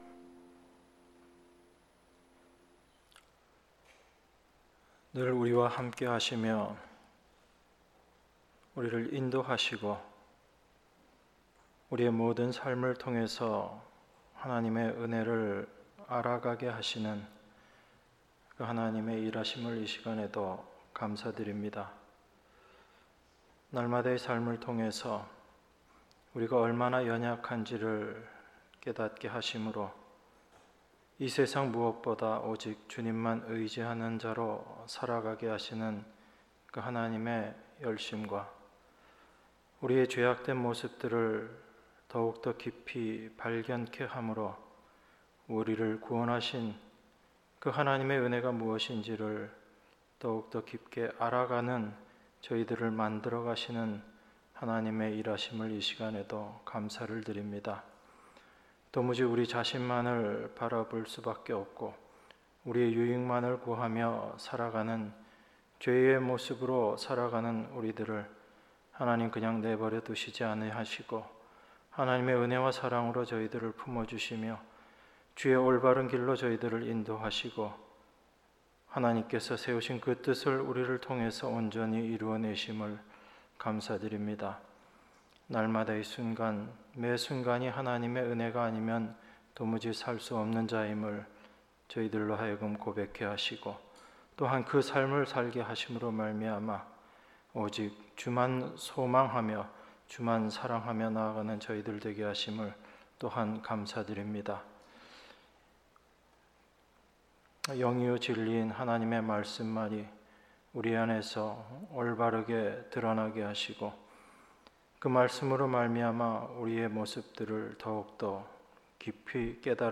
수요예배 시편 5편 1~12절